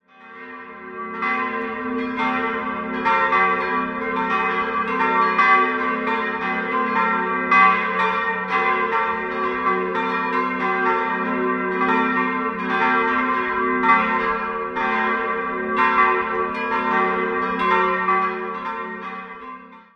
Im Jahr 1922 wurde sie nach Westen hin erweitert. 4-stimmiges Geläut: g'-b'-c''-gis'' Die kleinste Glocke ist historisch, die drei anderen wurden 1949 von der Gießerei Otto in Bremen-Hemelingen gegossen.